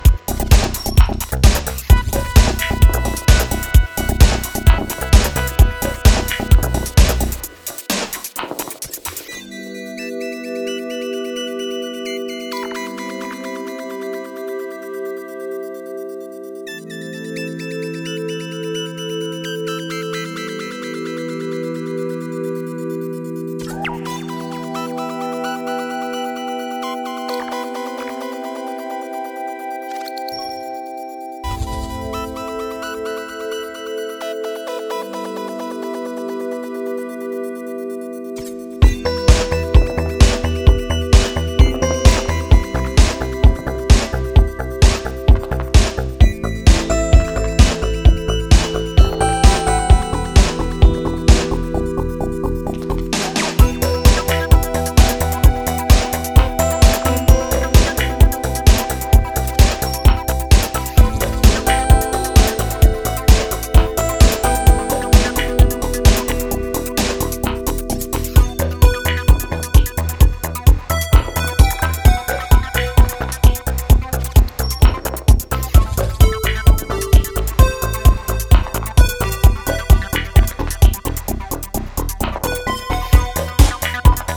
dark disco flavoured minimal techno